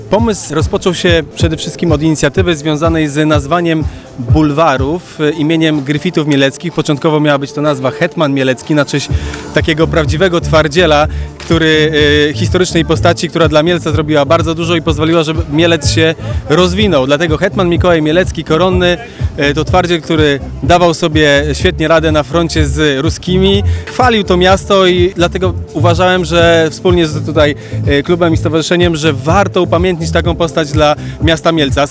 Liczne przeszkody, trudności i upał sprawiły , że bieg ten był iście wyczynowy. Mówi pomysłodawca Biegu Hetmana, Jakub Cena, radny miejski.